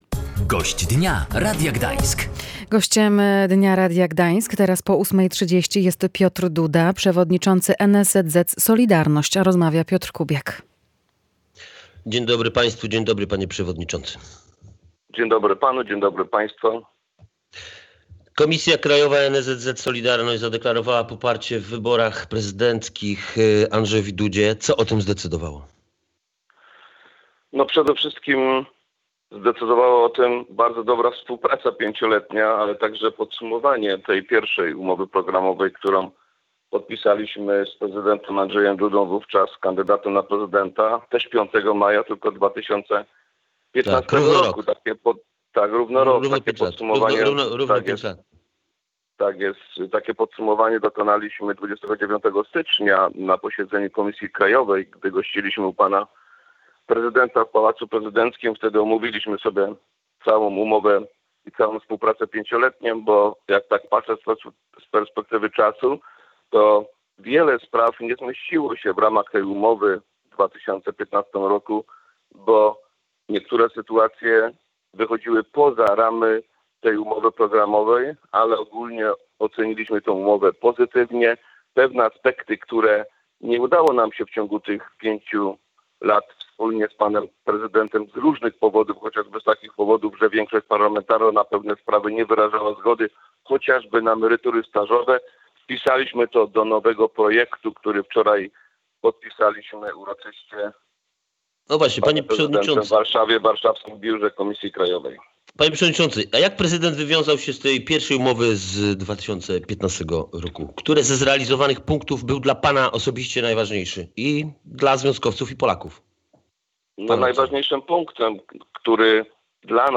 I jakie postulaty z podpisanej we wtorek umowy programowej są najważniejsze dla związkowców? O tym w rozmowie